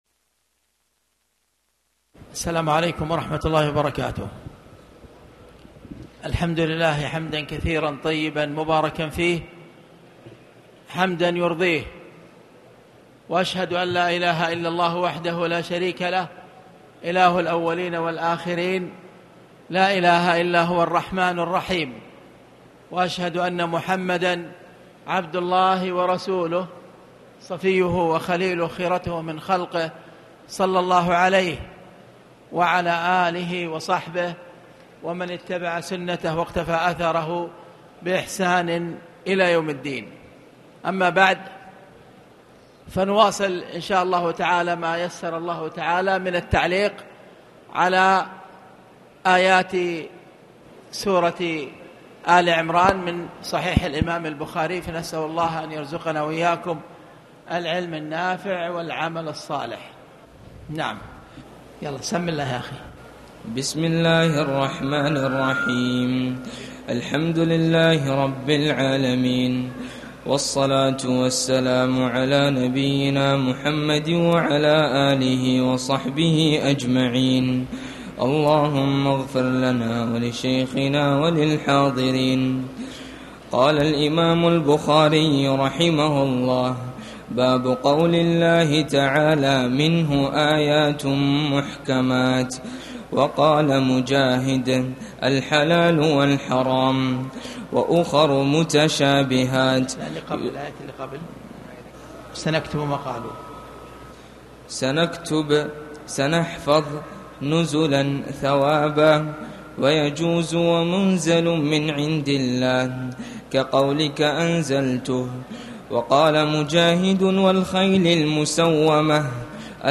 تاريخ النشر ١٠ رمضان ١٤٣٨ هـ المكان: المسجد الحرام الشيخ